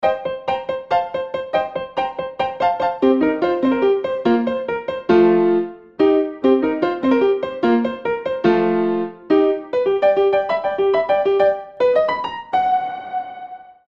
Рингтоны без слов
Пианино
Инструментальные